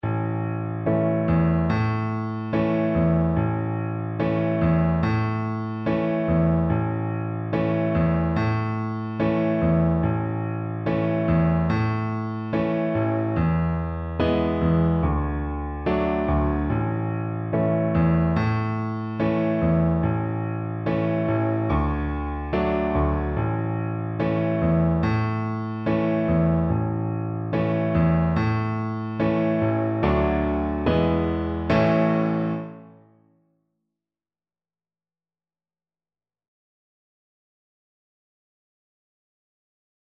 World Africa Congo-Brazzaville Kanzenzenze (Congo traditional)
Violin
4/4 (View more 4/4 Music)
A major (Sounding Pitch) (View more A major Music for Violin )
Moderato